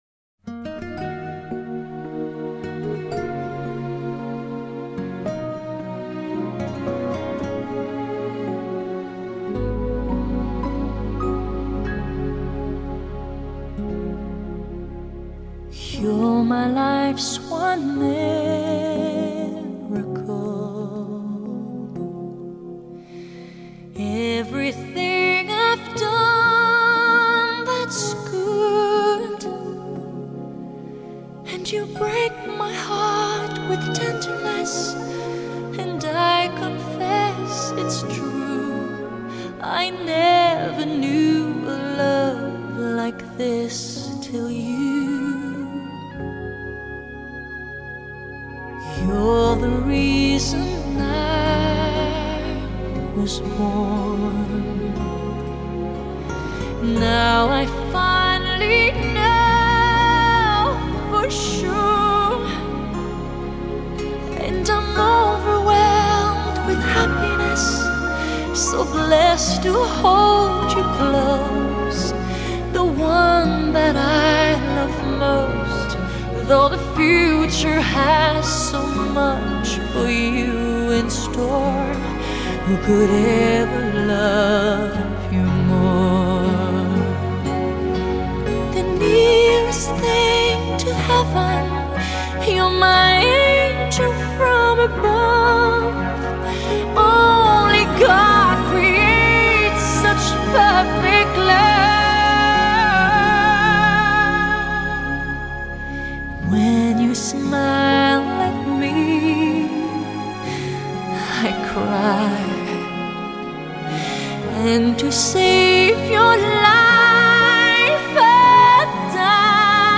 用温暖的音乐和深情的摄影共同来表现母爱的伟大和婴儿的可爱